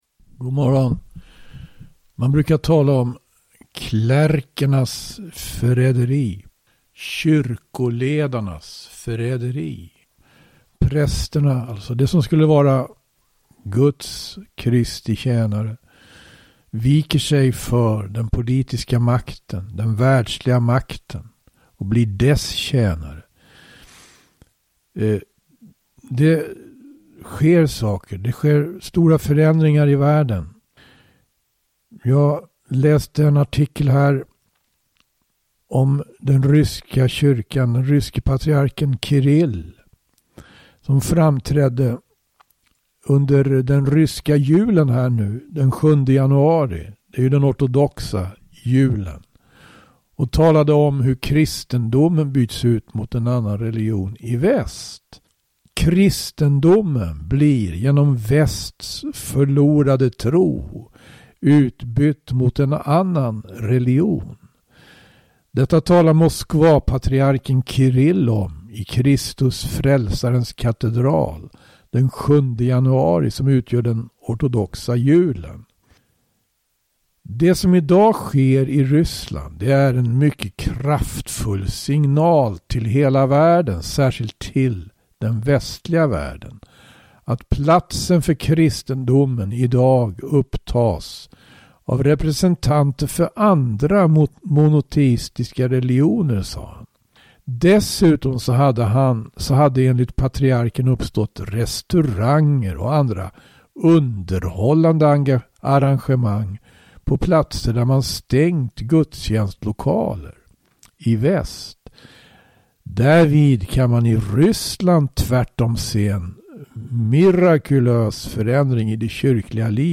läser ur bok